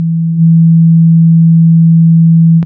这个声音是一个基于正弦波的亚低音。
这个声音是在Waldorf PPG VSTi上创建的。
标签： 低音 多重采样 PPG SubBass会
声道立体声